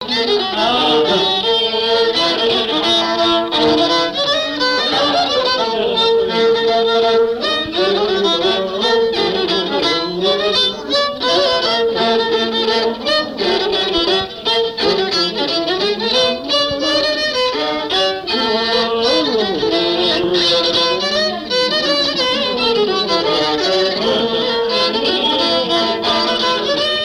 Mémoires et Patrimoines vivants - RaddO est une base de données d'archives iconographiques et sonores.
Chants brefs - A danser
danse : scottich trois pas
Pièce musicale inédite